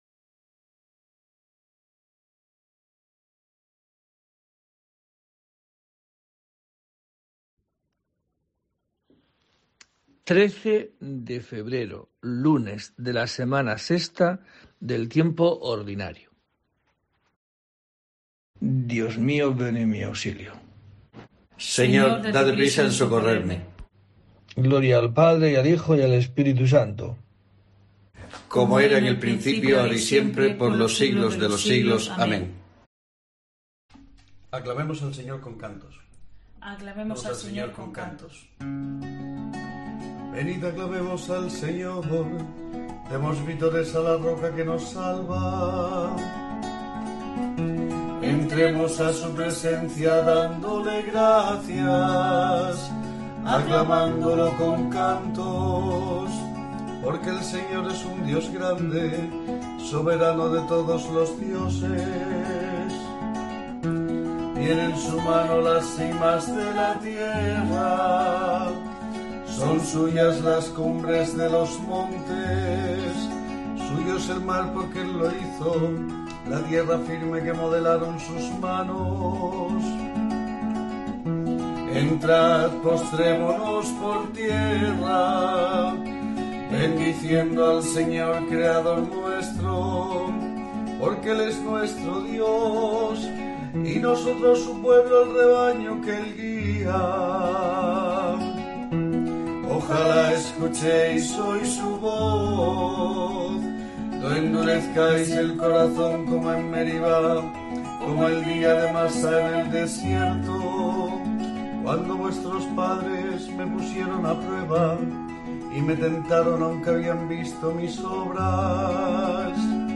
13 de febrero: COPE te trae el rezo diario de los Laudes para acompañarte